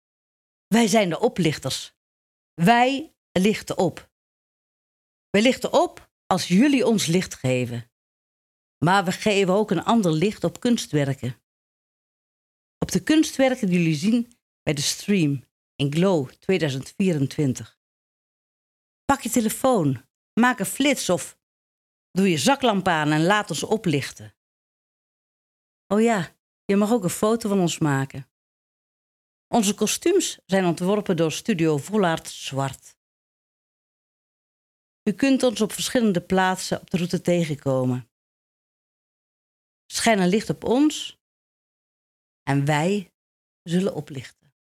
glow-audio-tour-oplichters.mp3